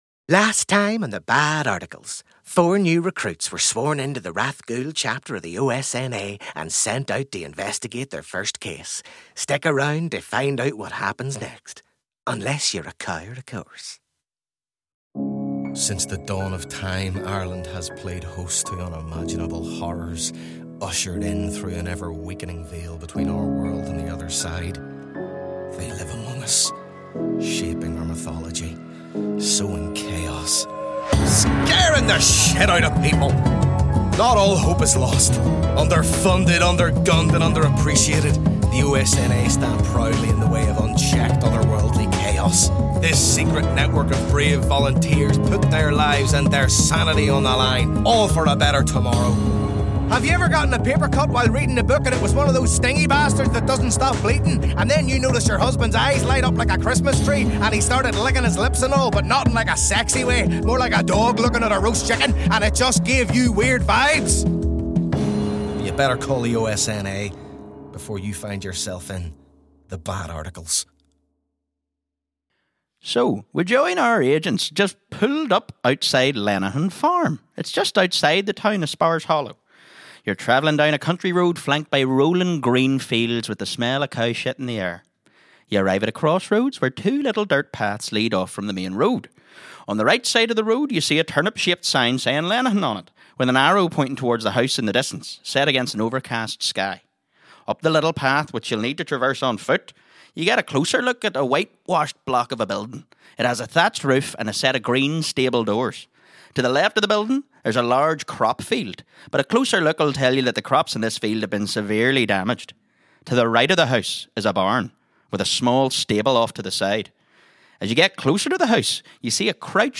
CONTENT WARNING: While the tone of the show is light-hearted and comedic, it contains references to lost children, as well as scenes featuring mild horror elements and light gore sound effects.